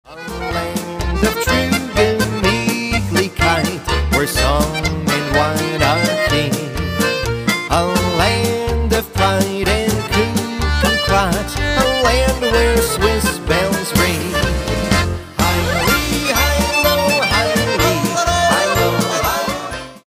A Milwaukee, Wisconsin Polka Band